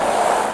dirt.ogg